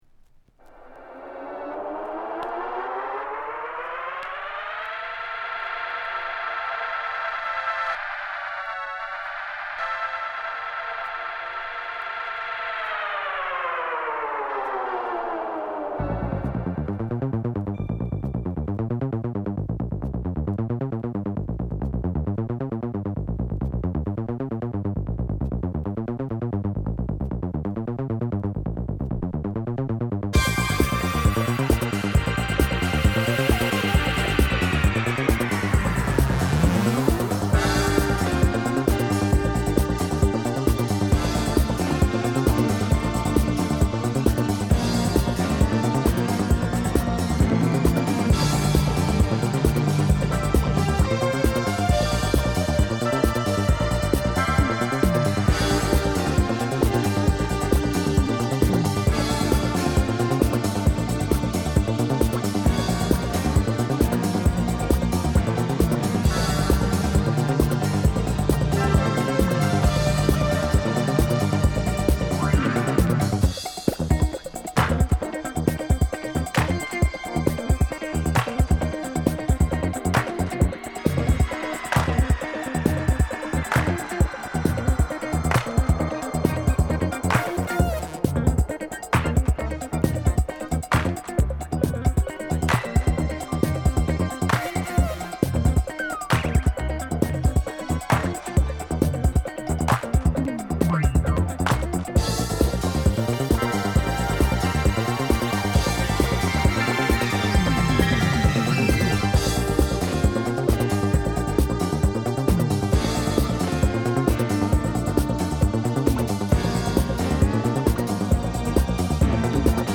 全体通してエレクトロ/スペイシーなシンセ使いがカッコ良い好作！